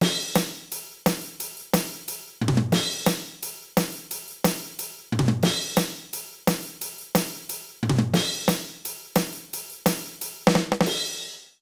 British ROCK Loop 177BPM (NO KICK).wav